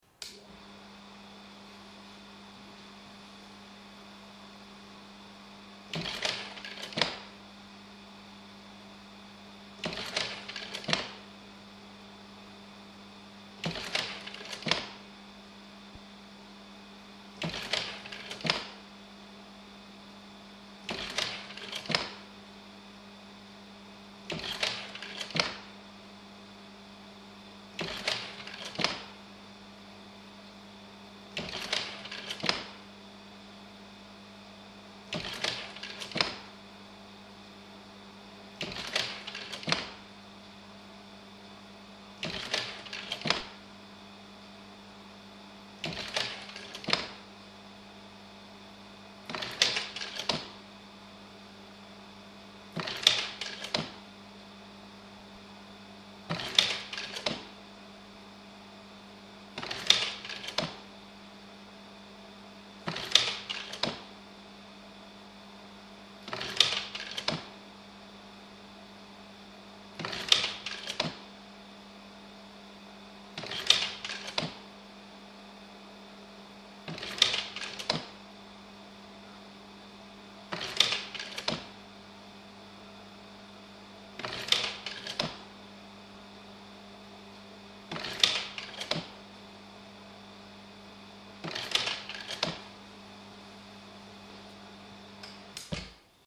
Slide projector
An old carousel slide projector containing 13 slides, taken out from the dark cupboard containing other such obsolete technology, and recorded for posterity.